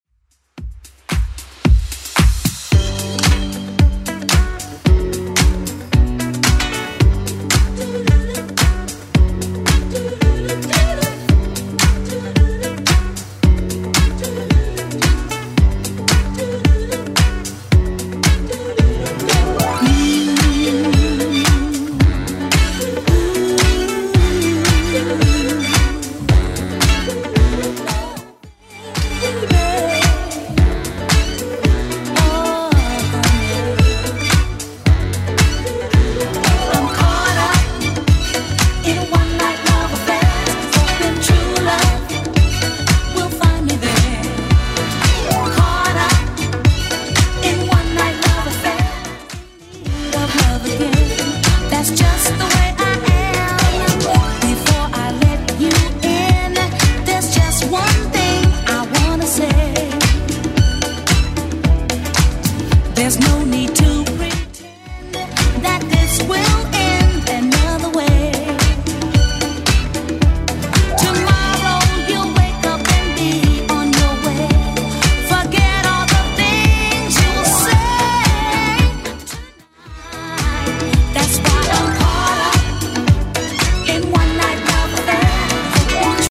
BPM: 112 Time